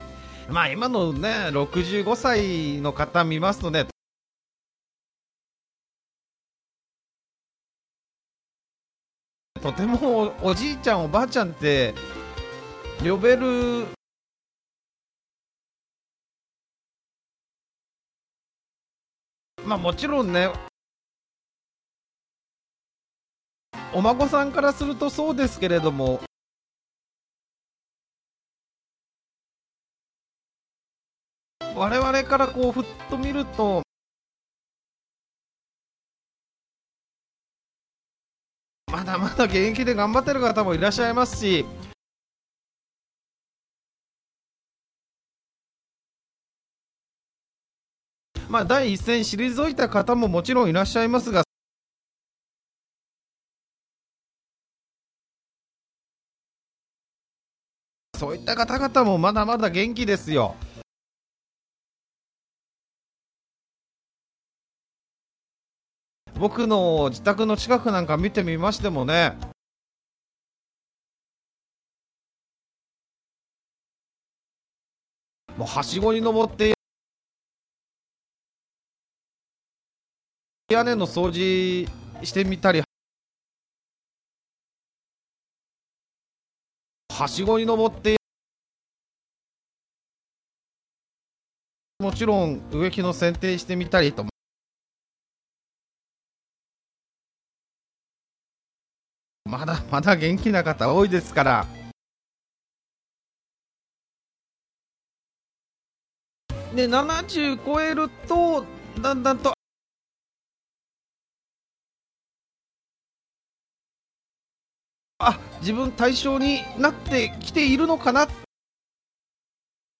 Slow Speed
Slow Speed with Pauses